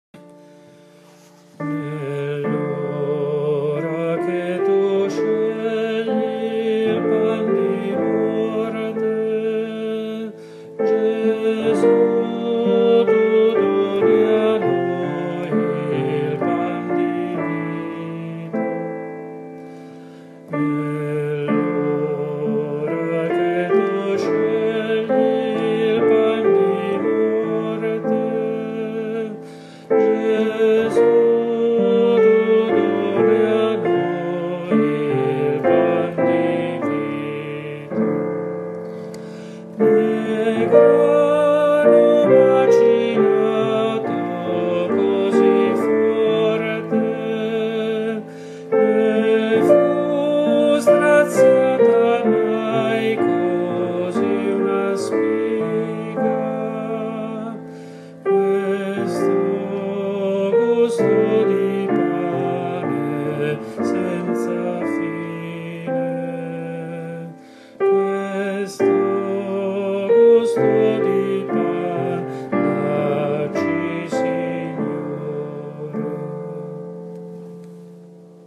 Solista ed assemblea alternandosi e ripetendo: